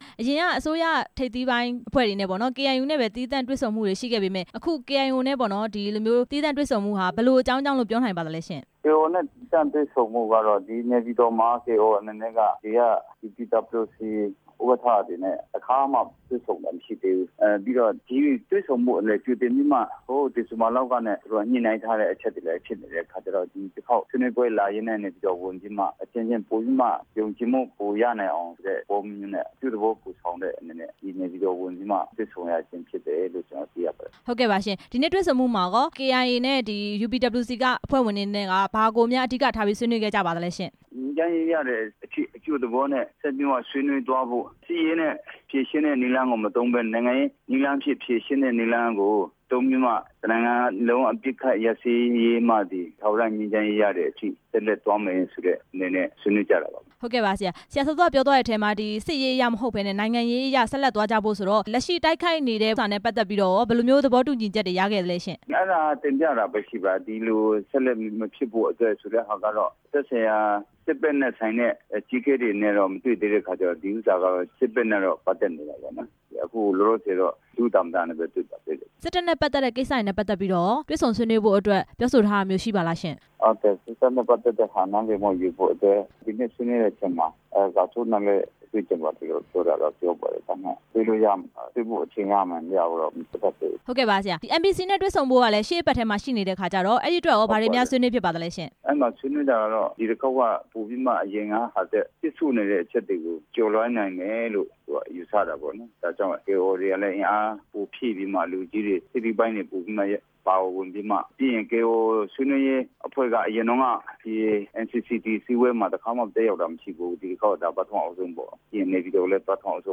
UPWC နဲ့ KIO တွေ့ဆုံပွဲ အကြောင်း မေးမြန်းချက်